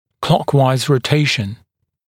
[‘klɔkwaɪz rə’teɪʃ(ə)n][‘клокуайз рэ’тэйш(э)н]ротация по часовой стрелке, вращение по часовой стрелке, передняя ротация (нижней челюсти)